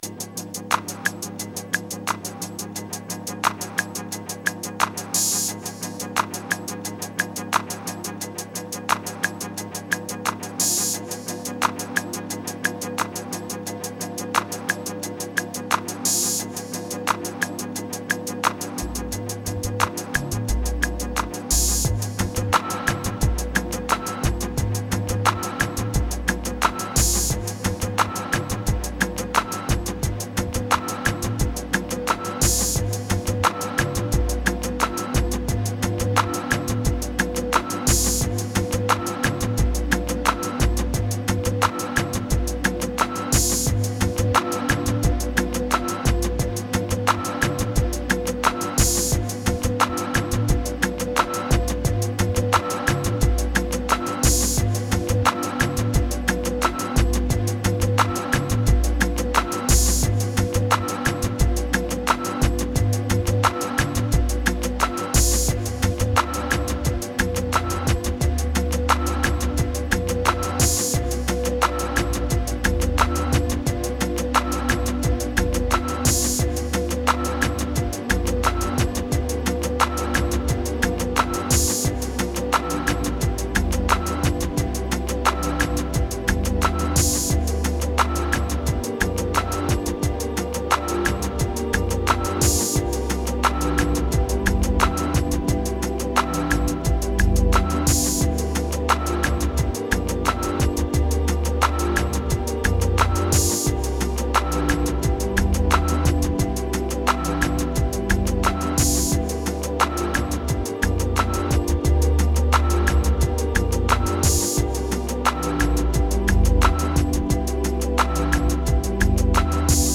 Turns out leaner than anticipated.
Electro Bass Dub Eq Static Model Hope Signal Lonely